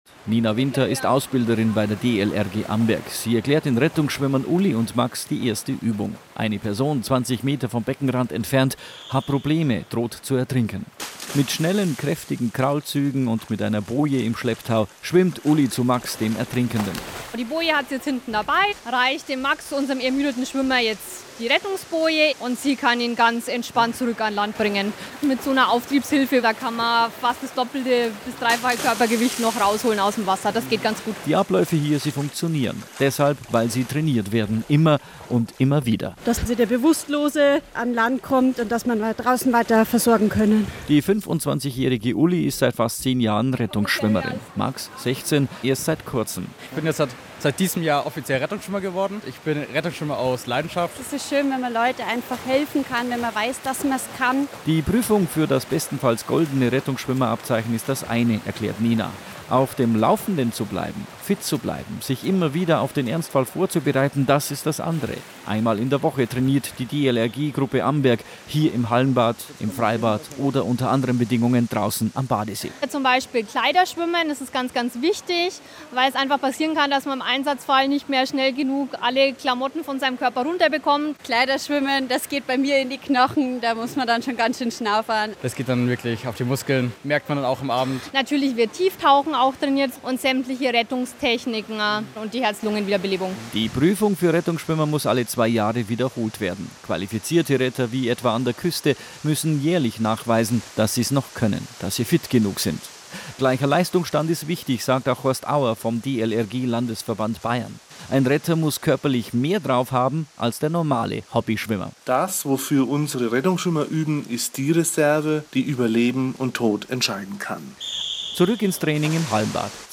Bayerischer Rundfunk bei Rettungsschwimmern der DLRG Amberg
Am Donnerstag den 14.7.2016 besuchte der Bayerische Rundfunk die DLRG Amberg.